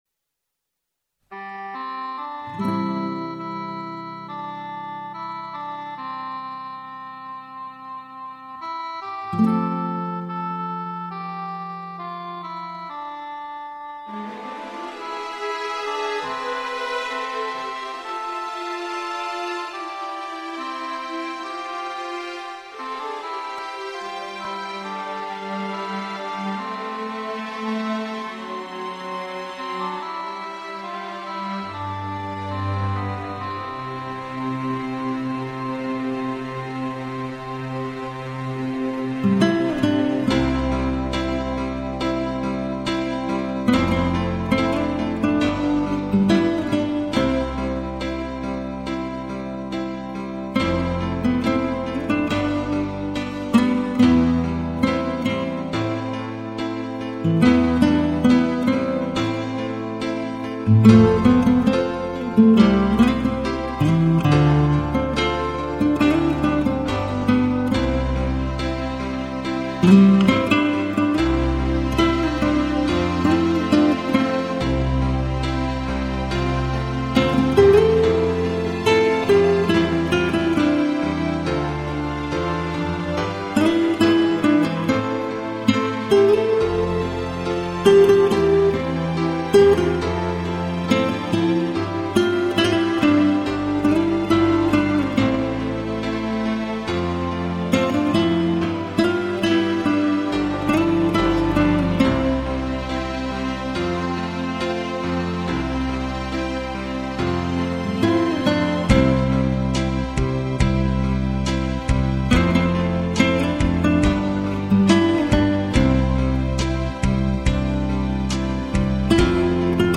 0140-吉他名曲此情不愉.mp3